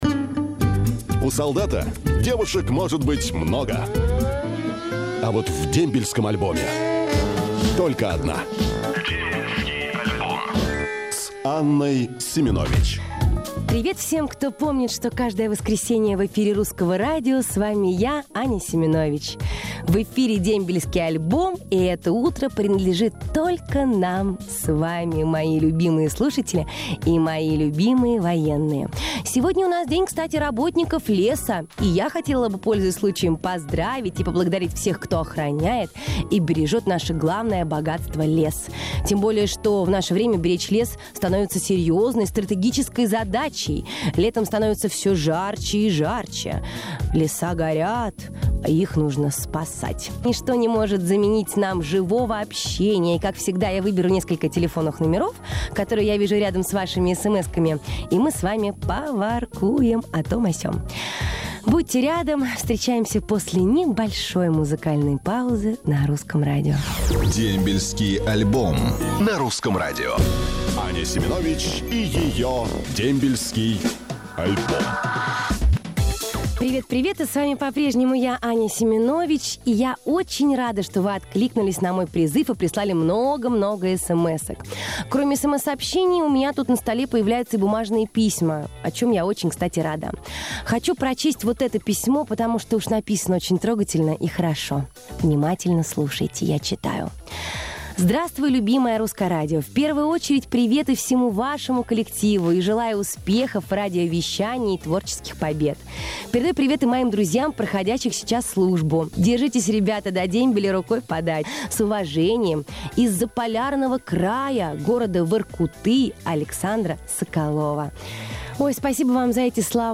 Запись эфира. Новая ведущая Анна Семенович. Дембельский альбом. Русское Радио.
В новом сезоне письма, СМС, звонки от солдат принимает на грудь Анна Семенович.
| Теги: Русское Радио, запись эфира, диджей, шоу, программа по заявкам, радиоведущий, Дембельский альбом, Анна Семенович